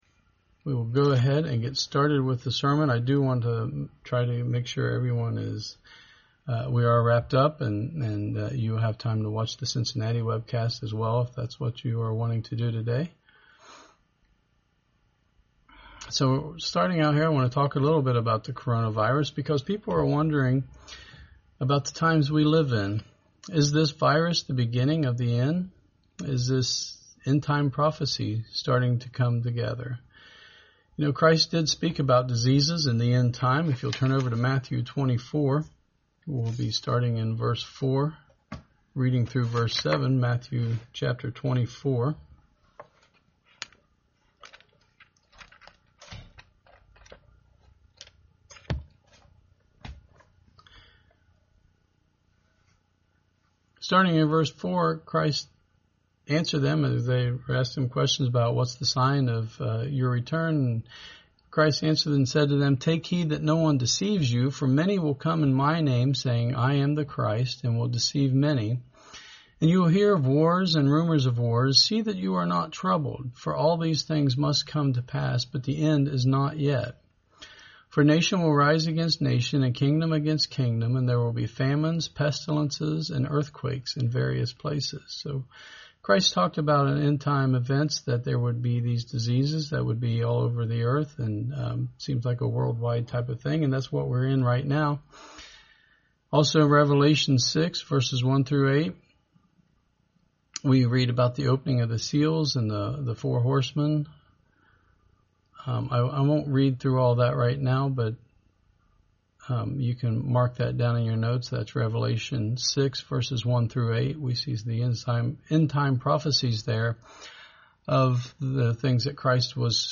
Sermons
Given in Dayton, OH